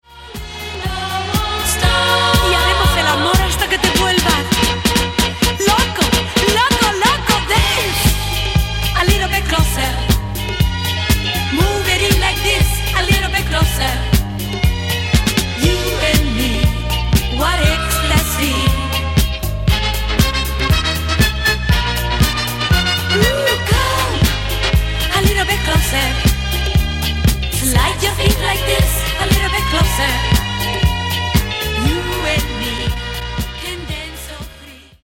dal favoloso gusto di flamenco